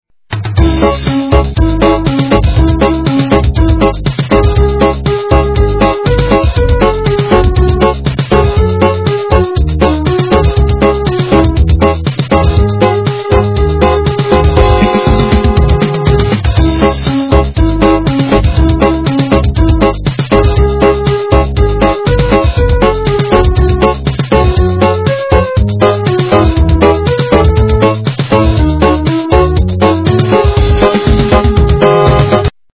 русская эстрада